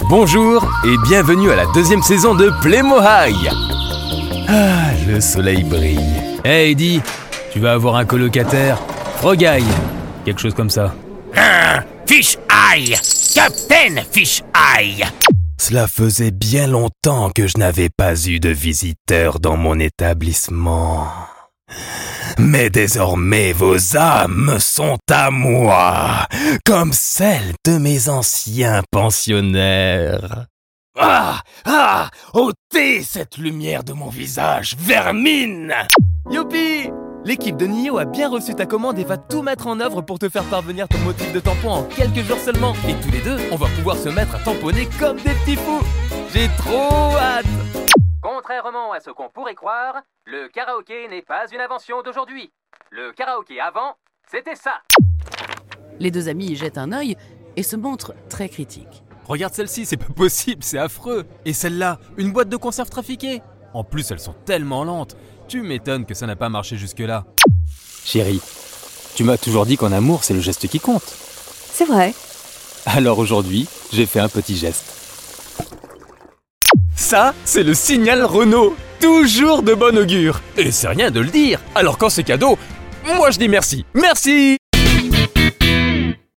Amable, Versátil, Joven, Natural, Maduro